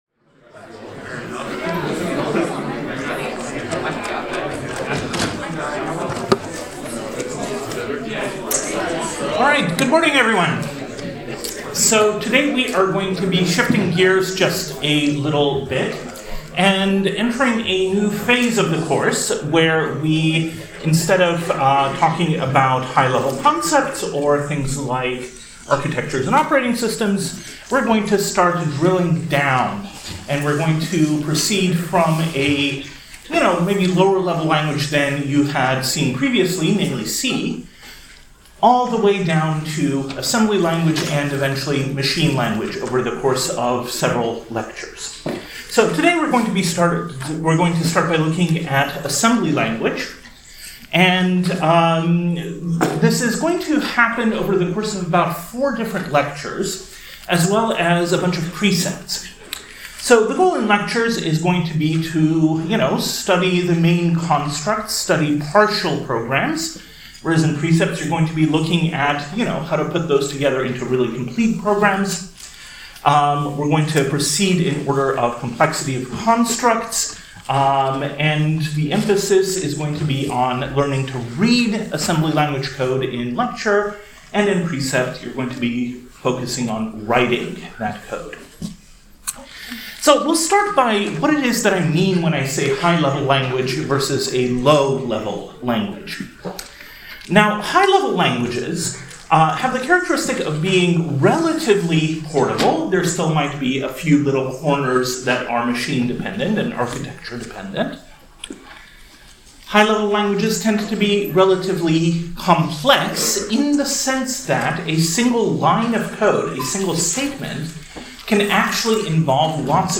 Thursday — Lecture 17: Assembly Language (part 1) Slides .